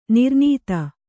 ♪ nirnīta